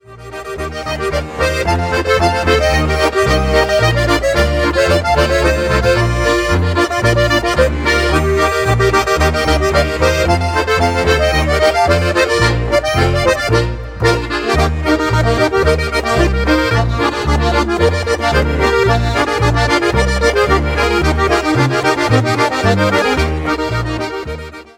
Schottisch